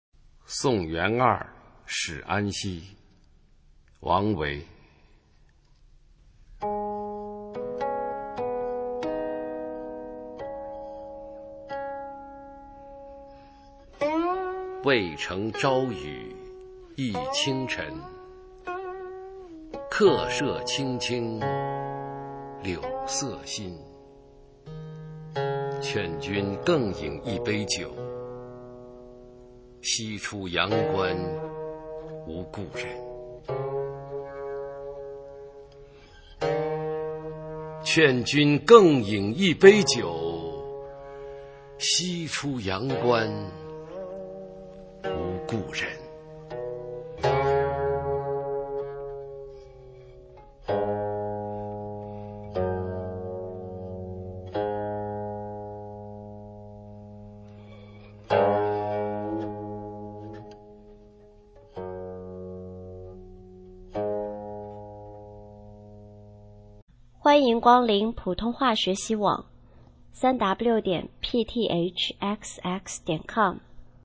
普通话美声欣赏：送元二使安西